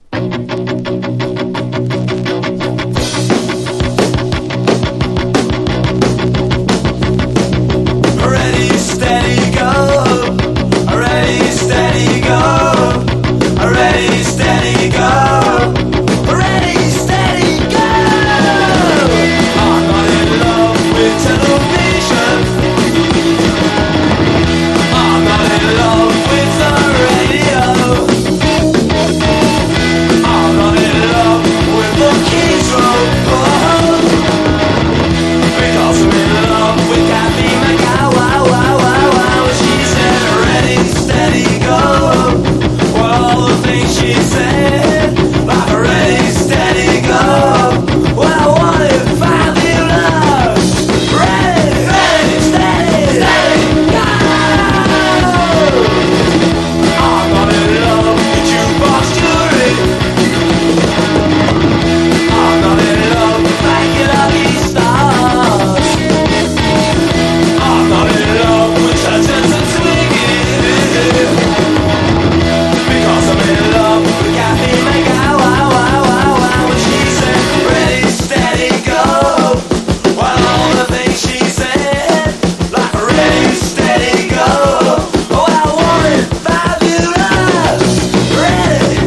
70’s ROCK